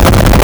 Player_Glitch [87].wav